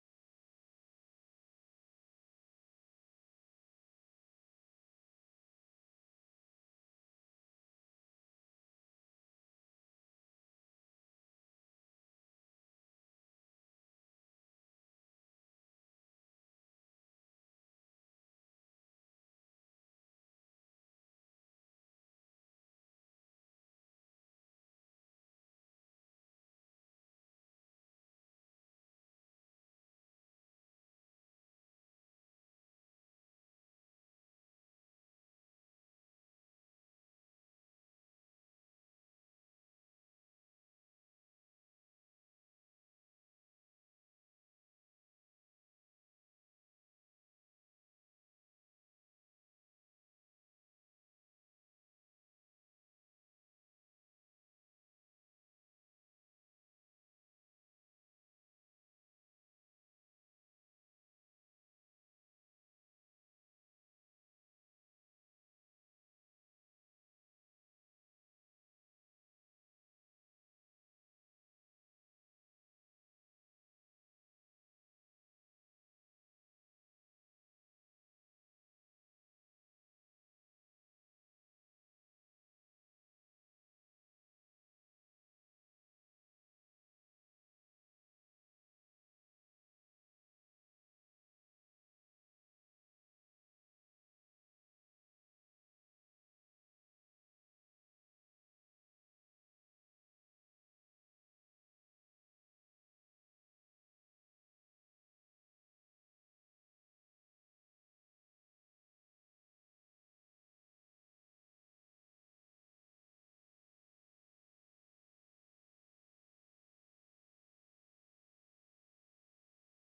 Jesus Only — J.O.Y. Sermon
Jesus-Only-J.O.Y.-Sermon-Audio-CD.mp3